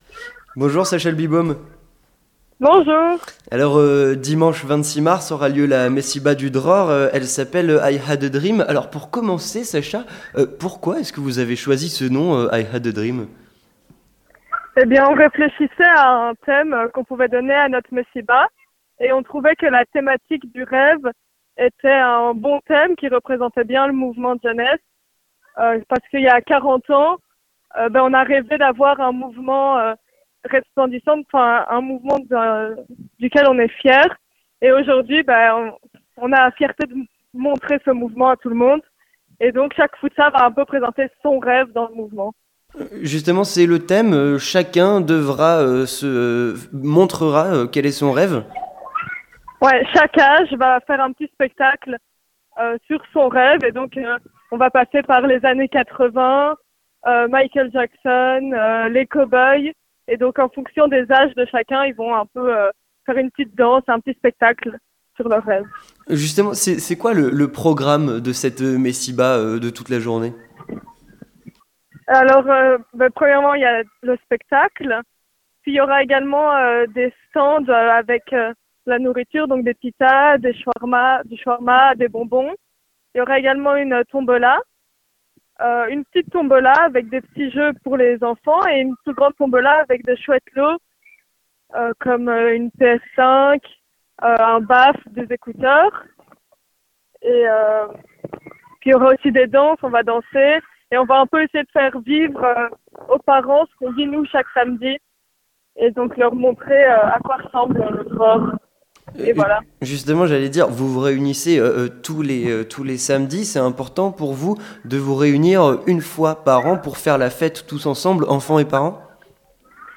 L'interview communautaire - La Messiba du Dror